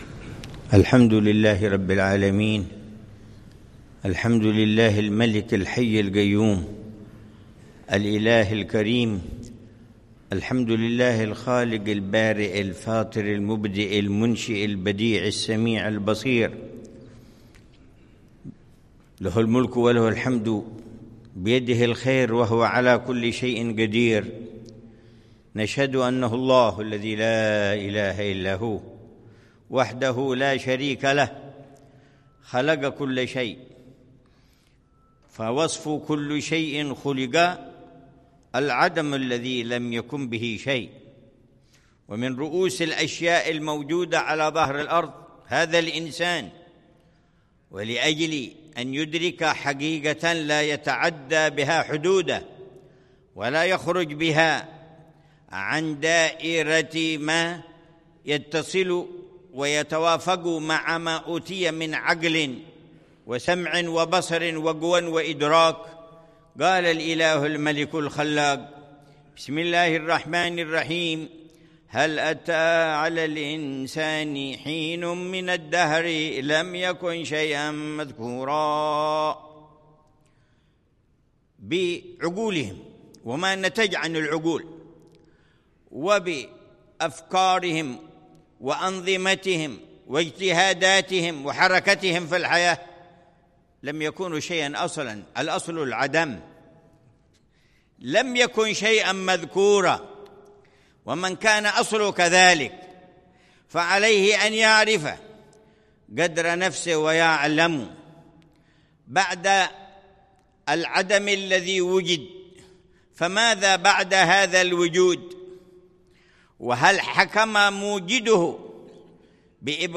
محاضرة العلامة الحبيب عمر بن محمد بن حفيظ، في جامع آل البيت، في مدينة صلالة، سلطنة عمان، ليلة الثلاثاء 10 ربيع الأول 1447هـ بعنوان: